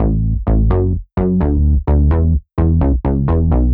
Index of /musicradar/french-house-chillout-samples/128bpm/Instruments
FHC_SulsaBass_128-A.wav